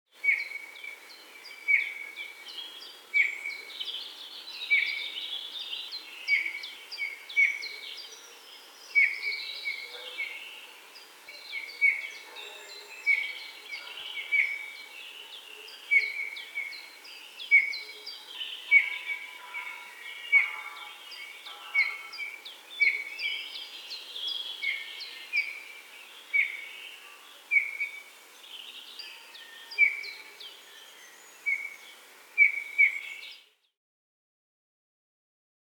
Вы можете слушать и загружать их пение в разных вариациях: от нежных переливов до звонких трелей.
Снегирь звонко кричит в зимнем лесу